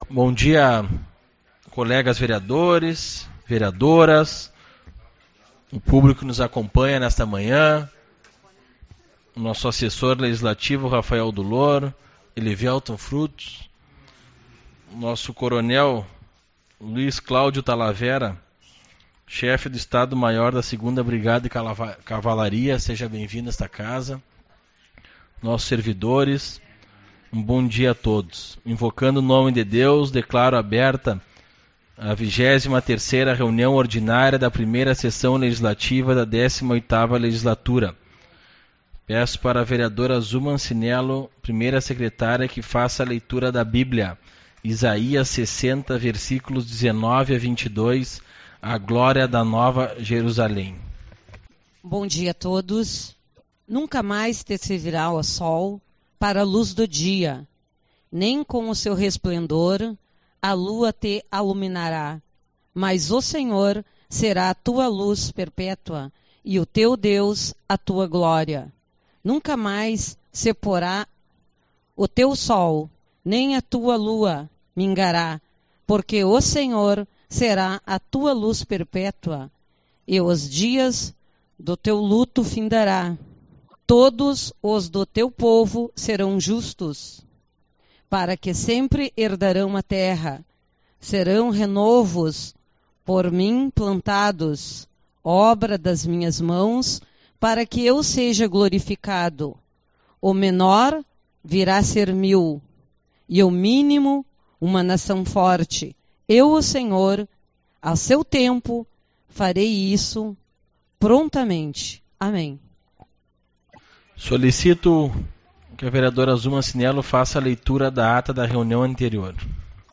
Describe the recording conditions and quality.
20/04 - Reunião Ordinária